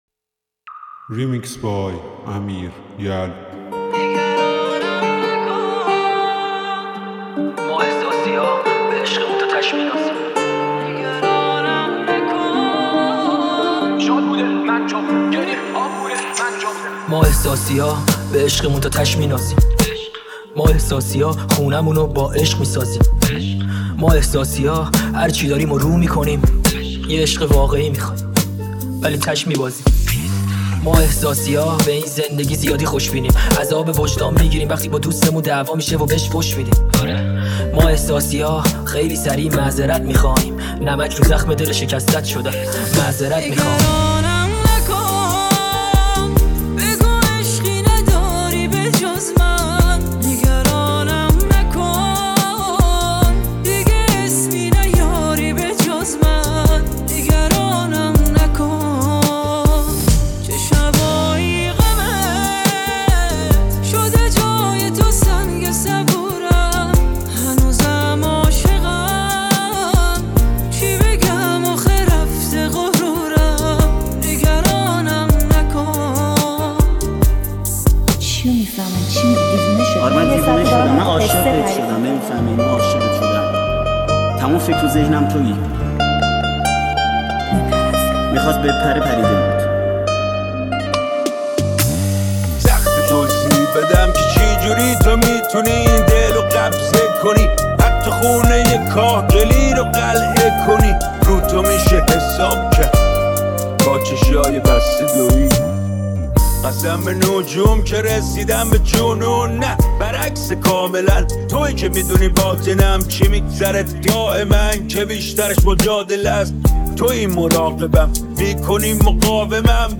دانلود ریمیکس جدید رپ